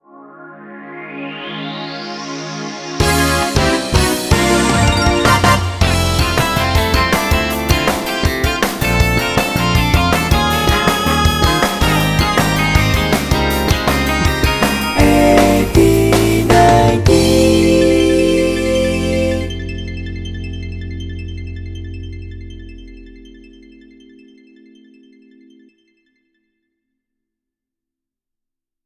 事業ではなく恐縮ではございますが…友人達と組んでいるシティポップスのカバーバンド「8090」（はちまるきゅうまる）のライブのため、オープニング曲（出囃子）を作りたいと思い、勢いで作編曲いたしました。一昔前、FMラジオ等で（時報の直後など）自局の宣伝として流れていた曲のようなイメージです。
当方で所有するソフトウェア音源を駆使して演奏プログラミングを行なった後、生演奏のベースギター・コーラス（私一人で18トラック歌っております）をオーバーダビングし、プラグインエフェクトを用いてダイナミクス調整・ピッチ補正・ミキシング・マスタリングを施しました。